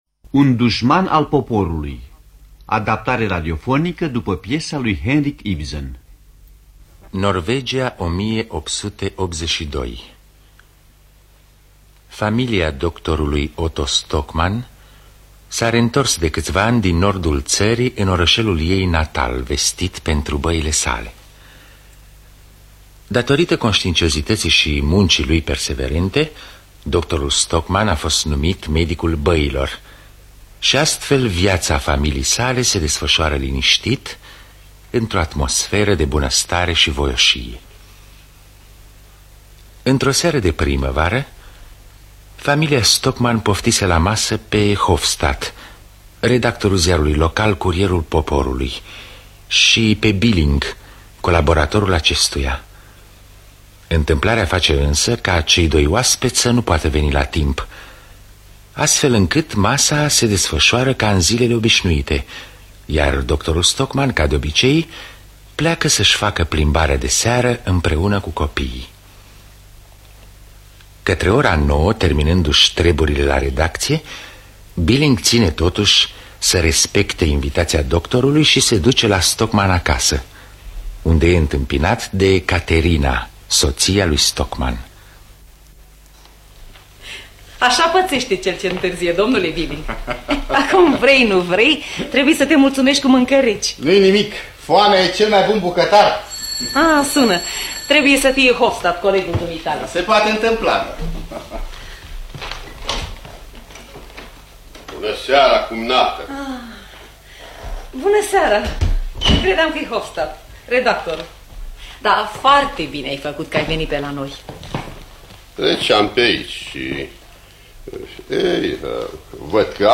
Un dușman al poporului de Henrik Ibsen – Teatru Radiofonic Online